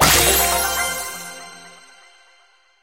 brawl_lootegg_open_3.ogg